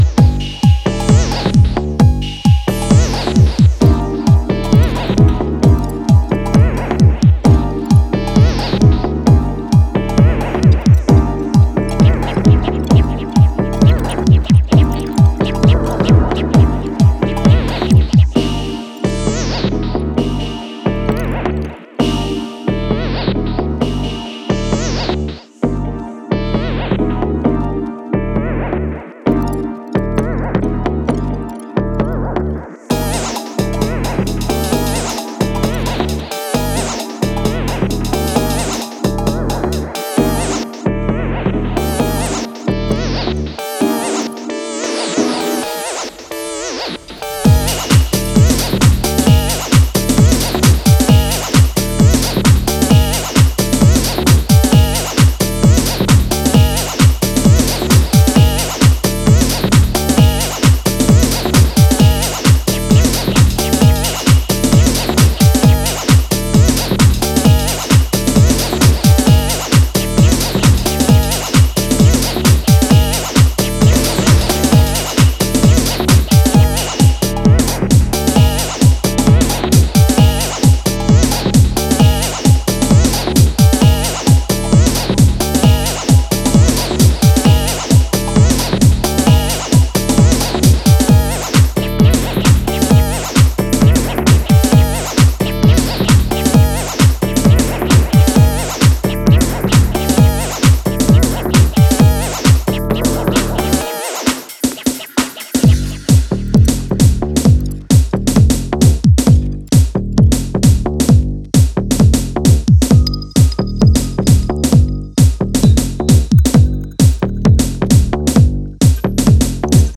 house tracks. All tunes were recorded in Montmartre, Paris